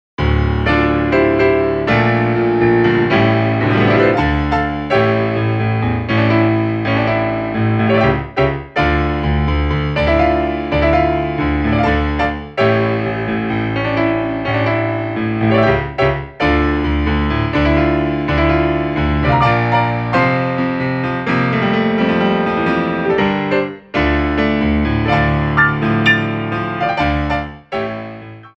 Grands Battements en Clôche
3/4 (8x8)